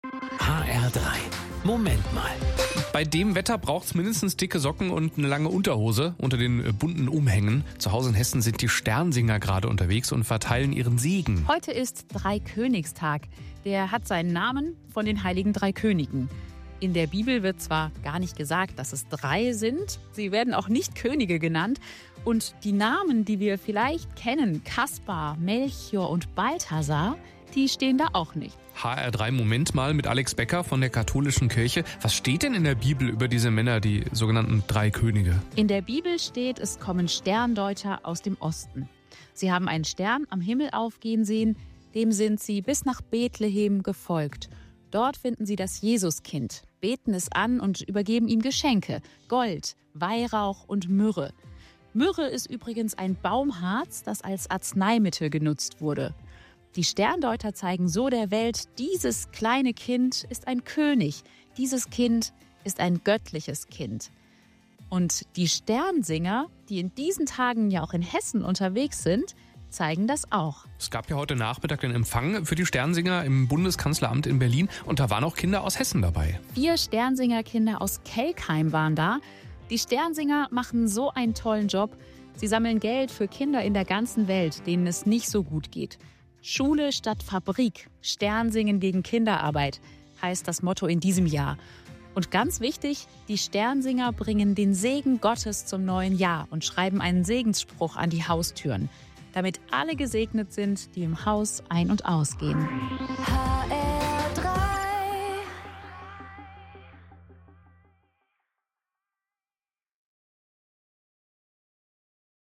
Katholische Pastoralreferentin Frankfurt